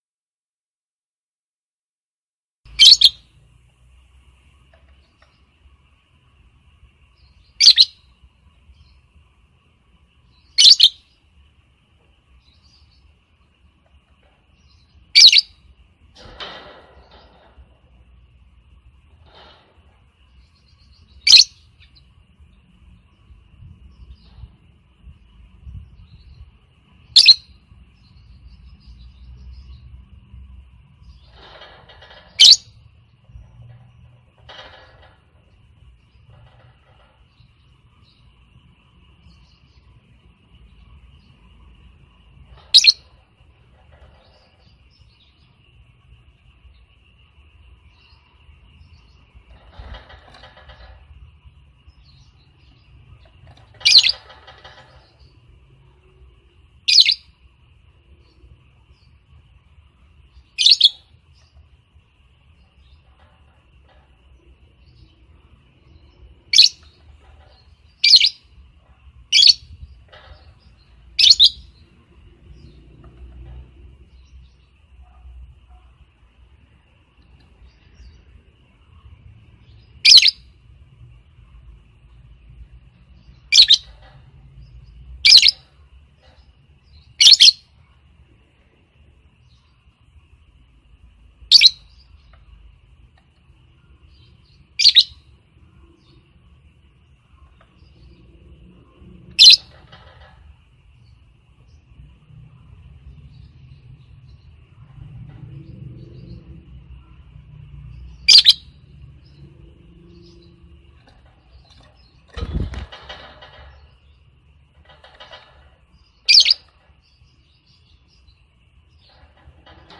Download suara lovebird betina mp3 sedang birahi gacor ngekek panjang untuk memancing birahi jantan agar minta kawin atau untuk memangil jantan
Mp3 Suara Burung Lovebird Betina